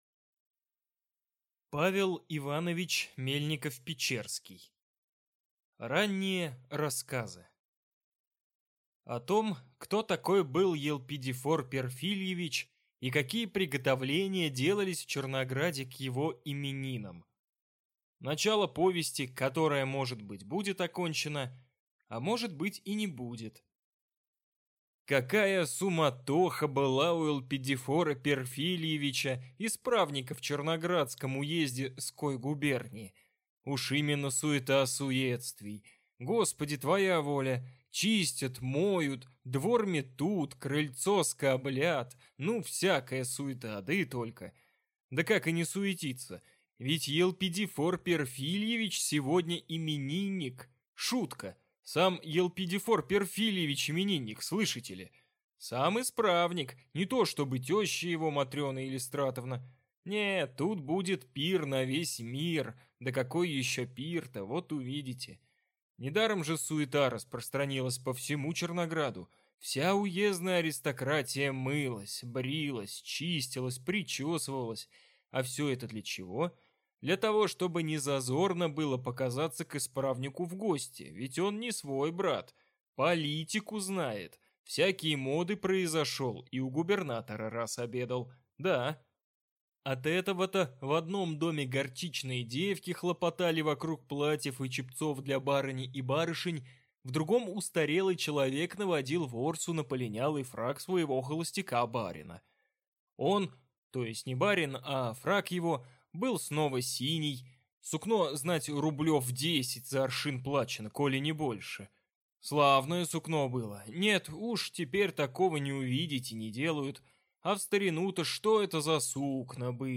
Аудиокнига Ранние рассказы | Библиотека аудиокниг